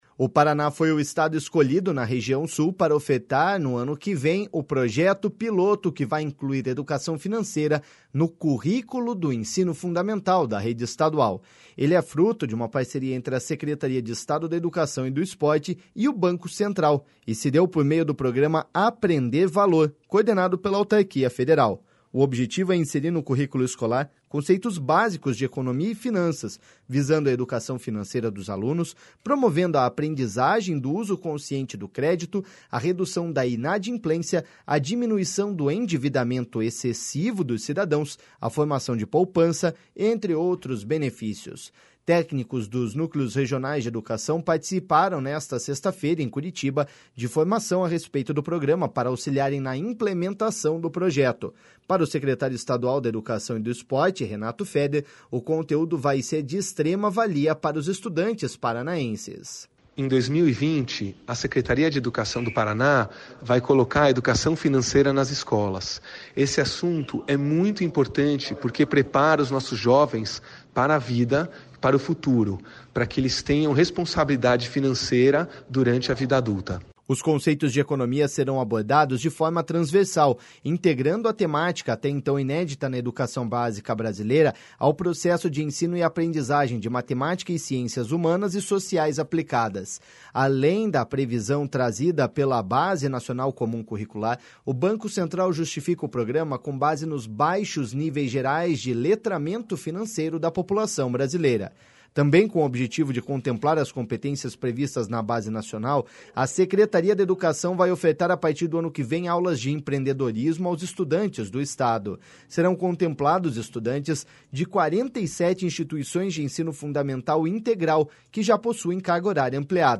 Para o secretário estadual da Educação e do Esporte, Renato Feder, o conteúdo vai ser de extrema valia para os estudantes paranaenses.// SONORA RENATO FEDER.//